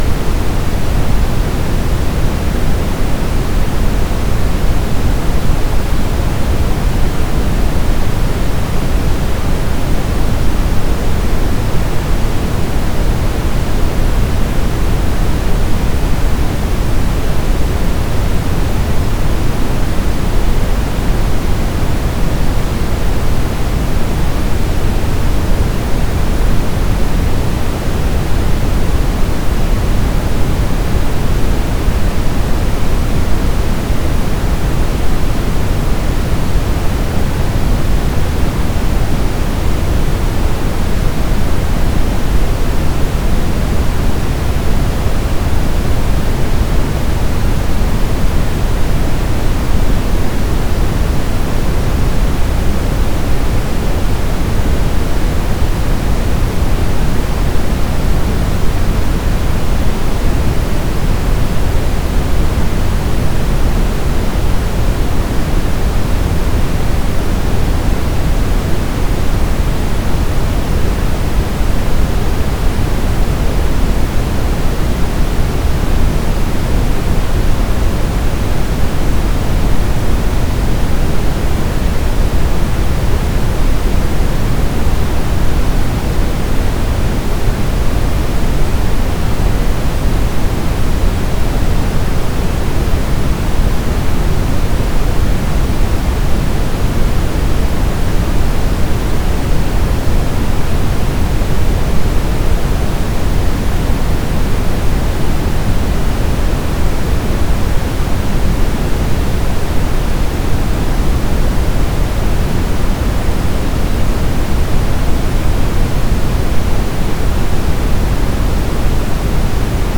Noise consists of sounds of virtually all audible frequencies.
brown_noise.mp3